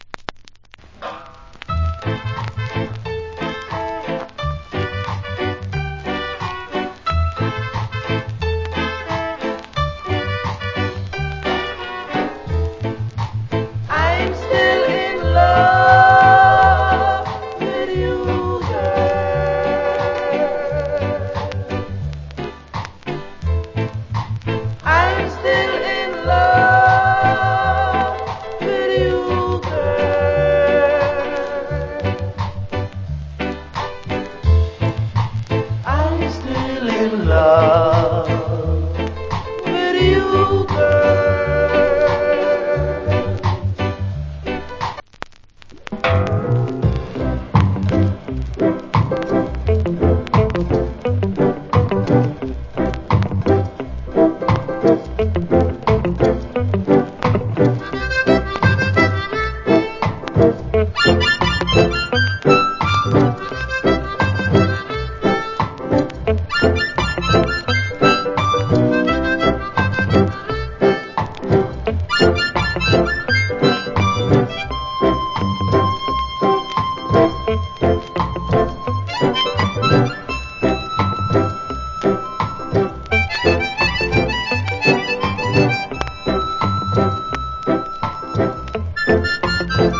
Great Rock Steady Vocal.